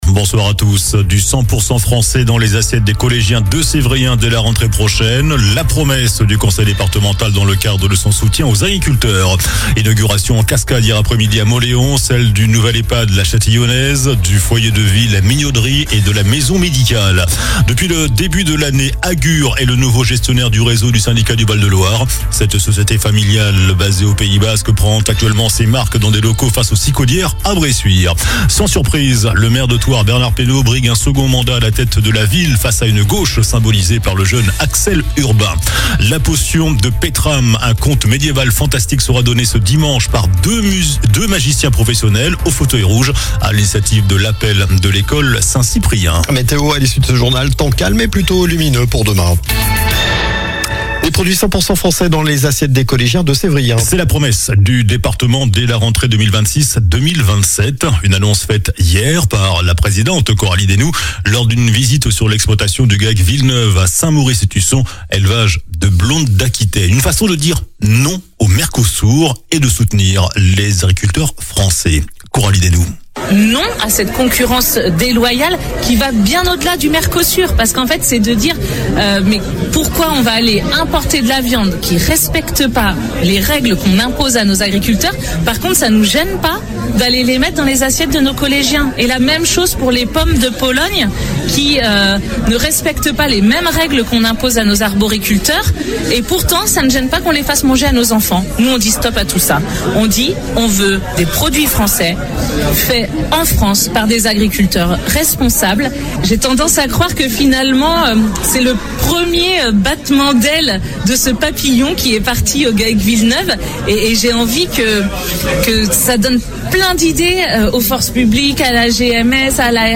JOURNAL DU MARDI 27 JANVIER ( SOIR )